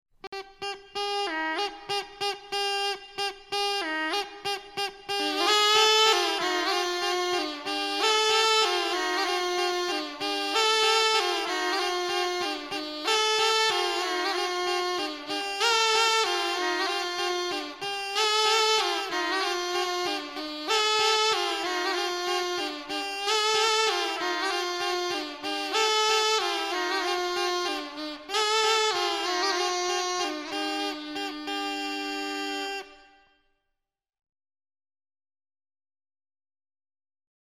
Birb. sutart.mp3